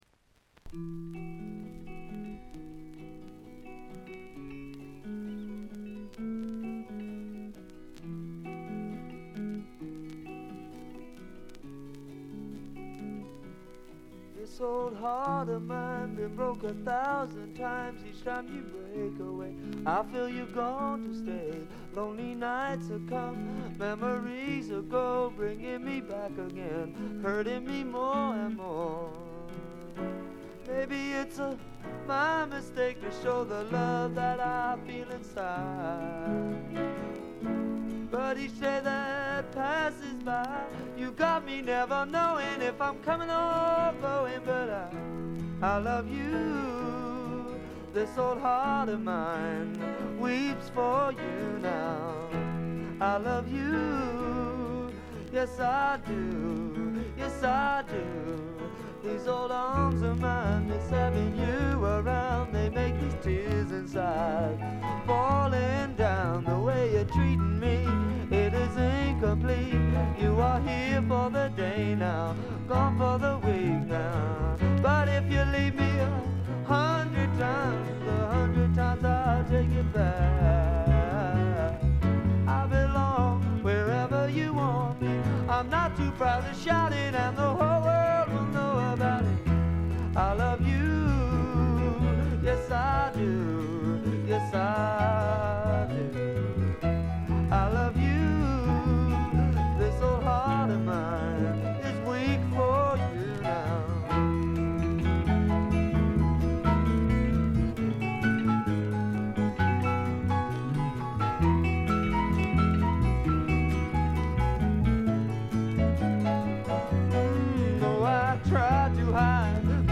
静音部で軽微なチリプチやバックグラウンドノイズ。
非トラッド系英国フォーク至宝中の至宝。
試聴曲は現品からの取り込み音源です。